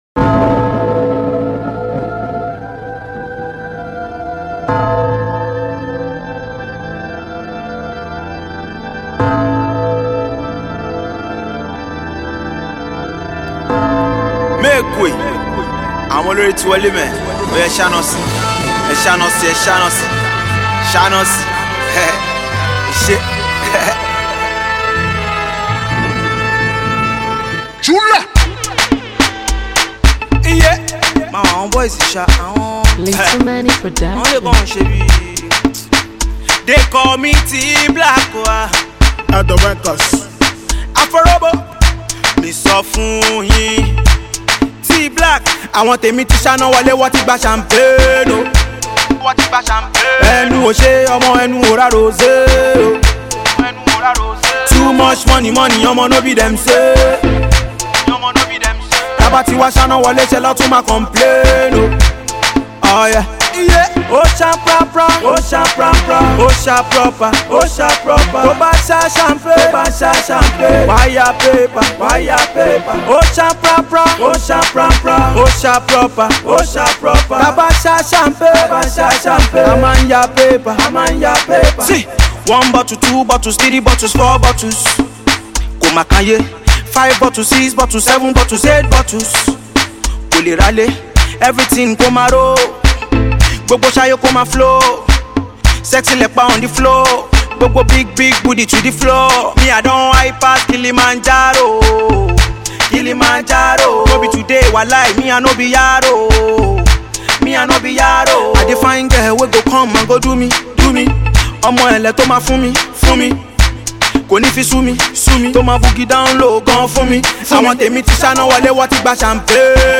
hot new club banger